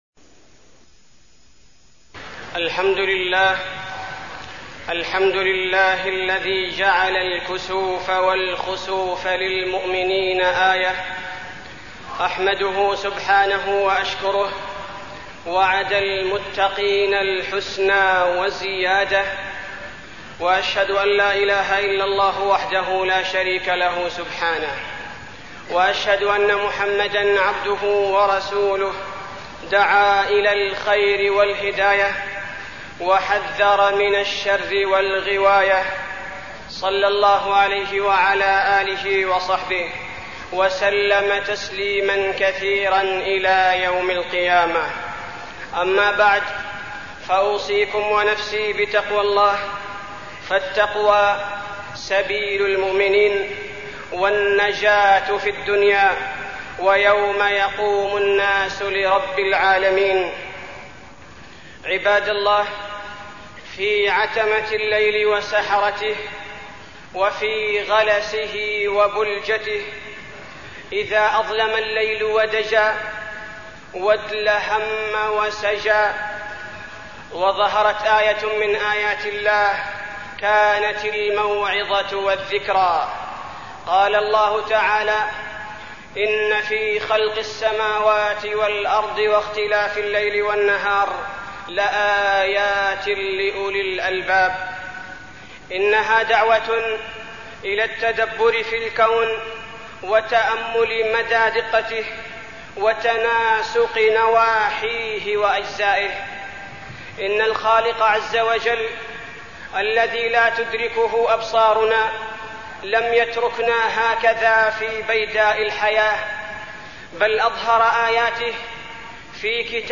تاريخ النشر ١٥ جمادى الأولى ١٤١٧ هـ المكان: المسجد النبوي الشيخ: فضيلة الشيخ عبدالباري الثبيتي فضيلة الشيخ عبدالباري الثبيتي آيات الخسوف والكسوف